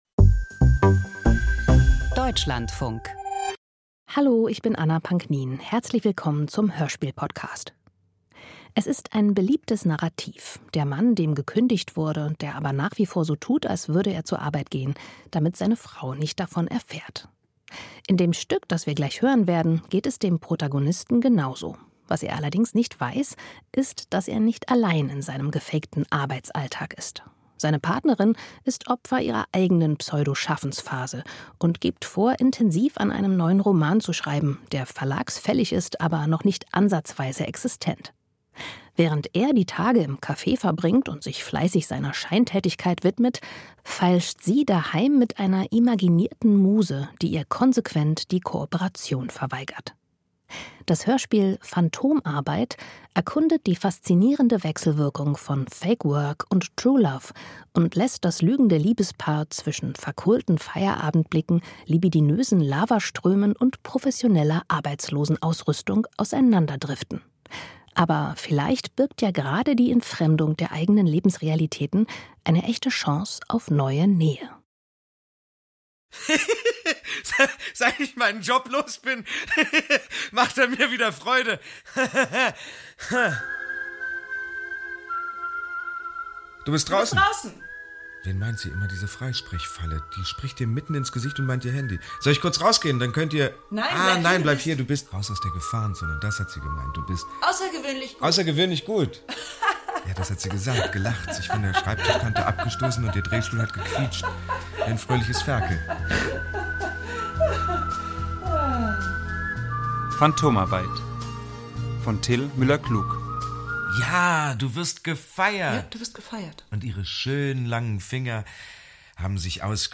Hörspiel über gefakte Arbeit und echte Liebe - Phantomarbeit